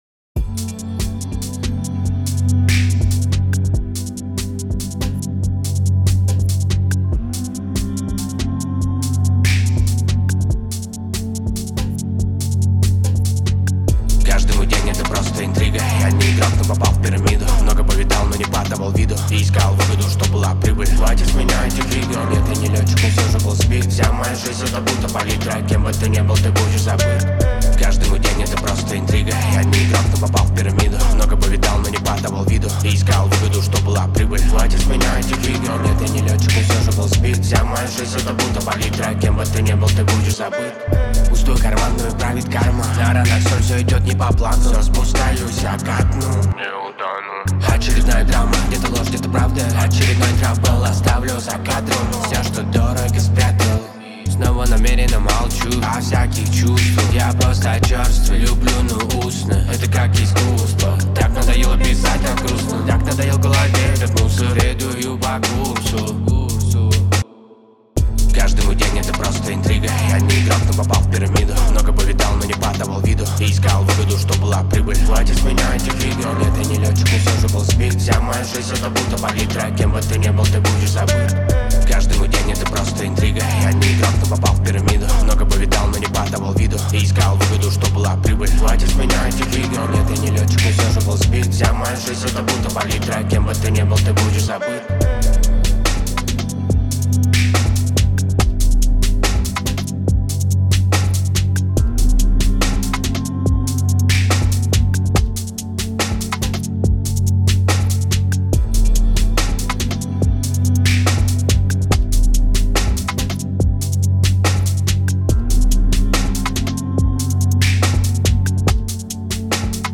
Рэп и Хип-Хоп